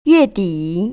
[yuèdĭ] 위에디  ▶